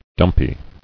[dump·y]